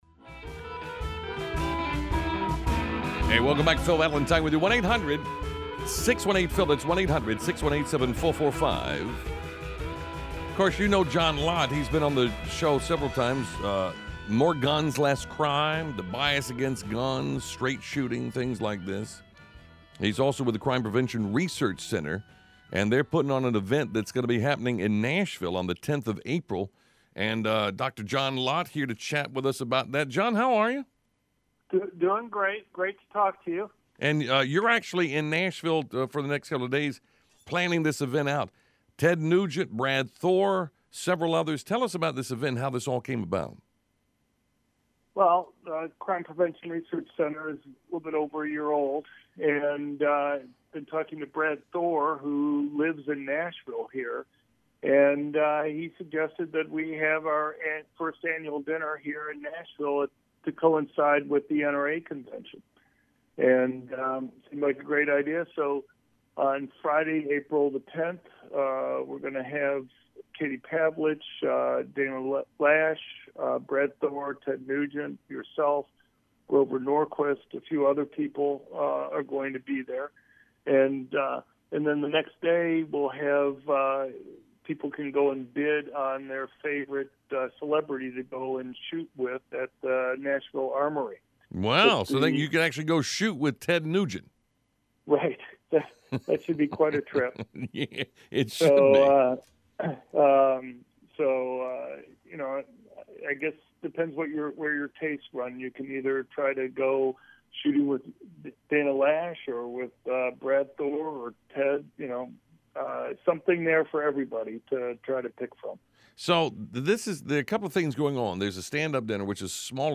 John-Lott-Valentine-Interview.mp3